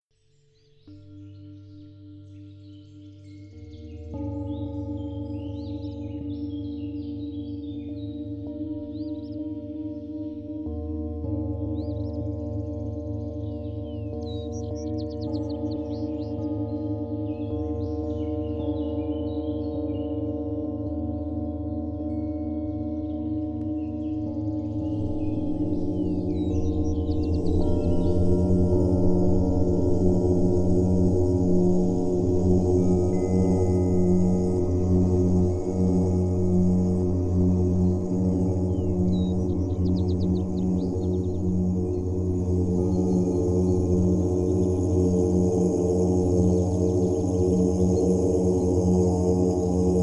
Doskonale nadaje się jako podkład do medytacji.